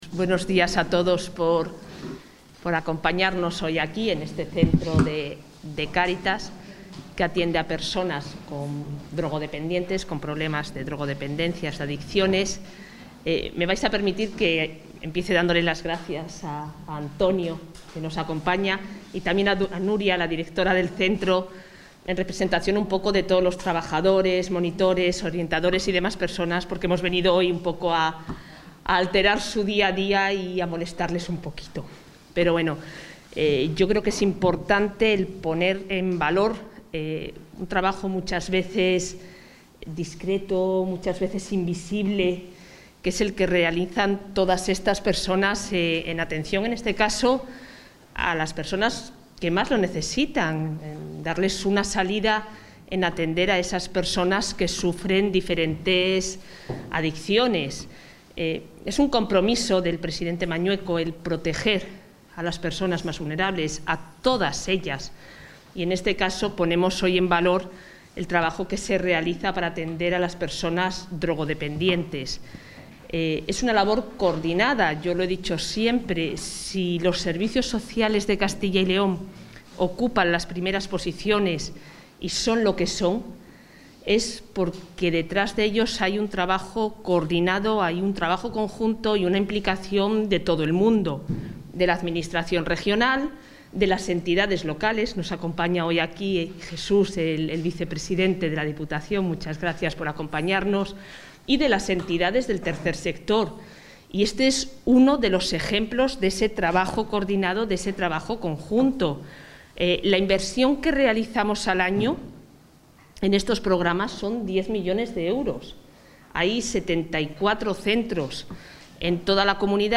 La titular de Familia e Igualdad de Oportunidades, Isabel Blanco, ha destacado esta mañana en Zamora el compromiso del Gobierno de...
Intervención de la consejera de Familia e Igualdad de Oportunidades.